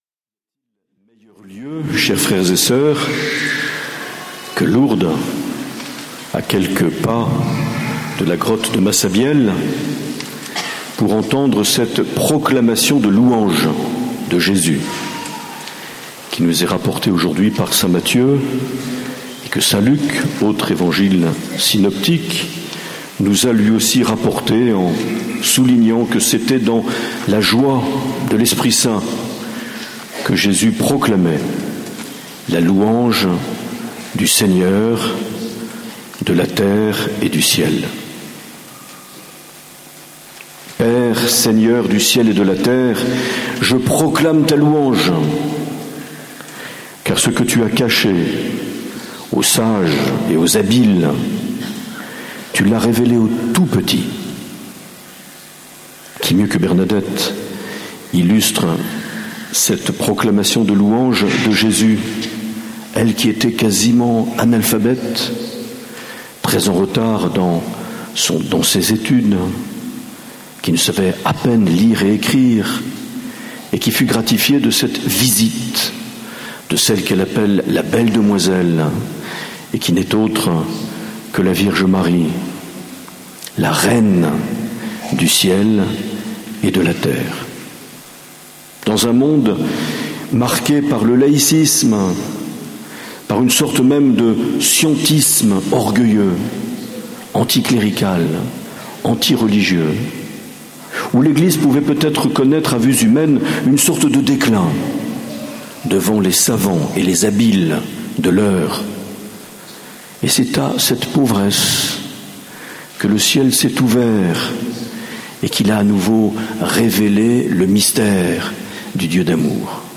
Enregistré en 2016 (Session Béatitudes Lourdes)
(12:17mn Mgr Robert le Gall) Lourdes 2016 - Homélie mardi (1.50 EUR)